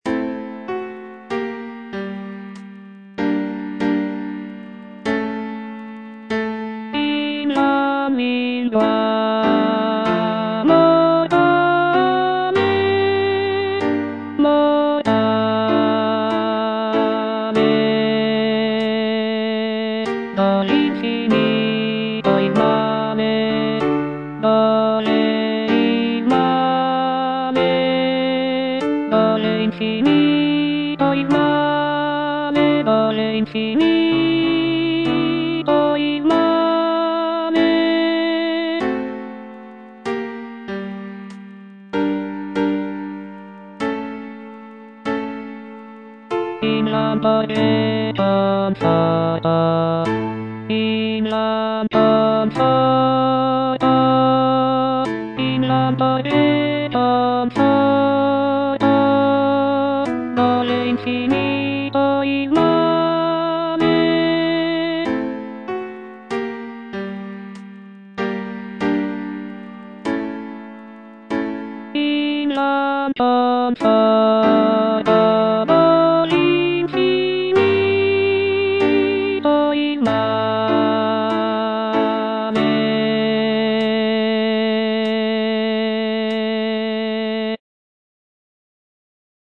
C. MONTEVERDI - LAMENTO D'ARIANNA (VERSION 2) Coro I: Invan lingua mortale - Alto (Voice with metronome) Ads stop: auto-stop
The music is characterized by its expressive melodies and poignant harmonies, making it a powerful and moving example of early Baroque vocal music.